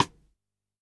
select-expand.ogg